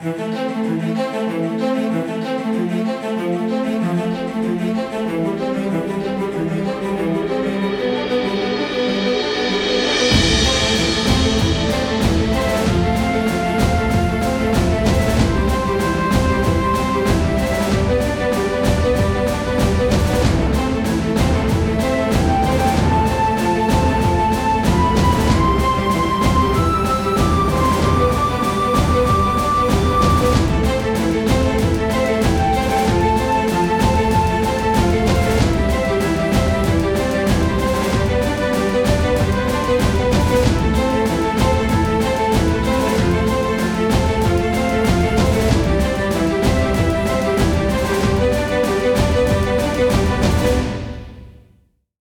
Game Music
Motivational Musics for Kid’s game